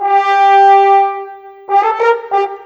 Rock-Pop 07 Horns 02.wav